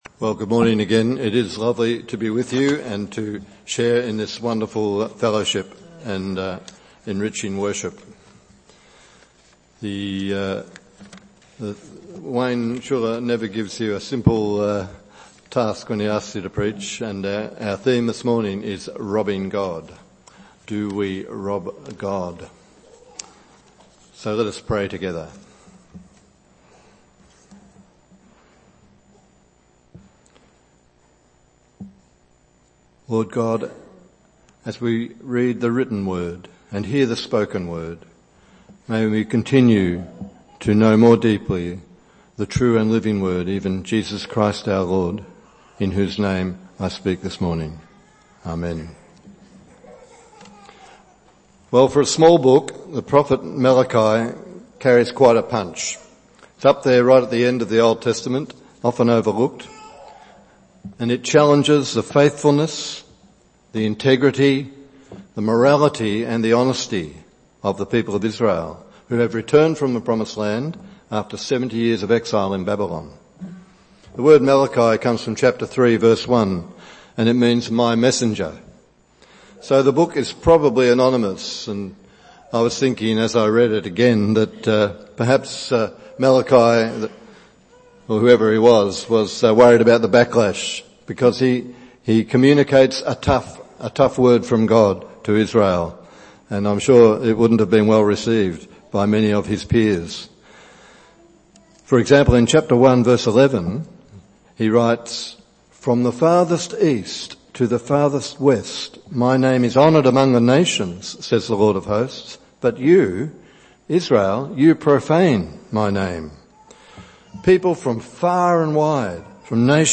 Bible Text: Malachi 3:6-12 | Preacher